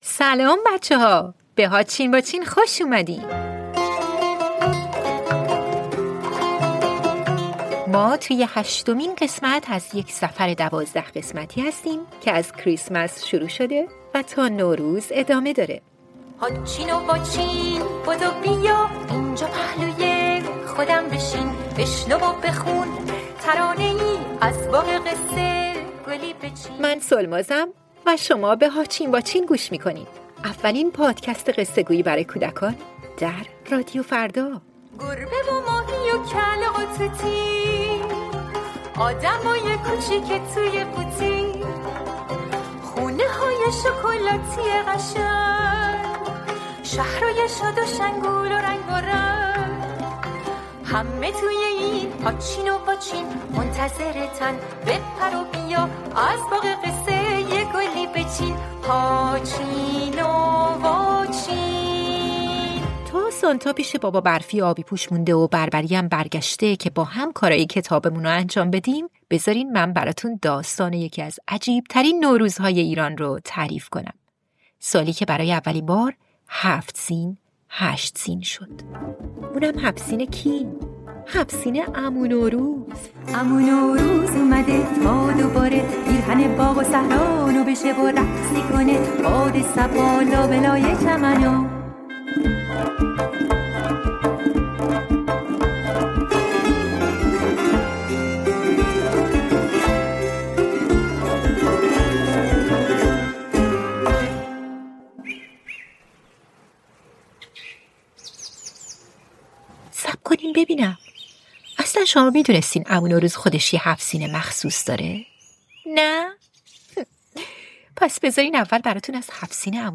پادکست «هاچین واچین» اولین کتاب صوتی رادیو فردا، مجموعه قصه‌های کودکان است.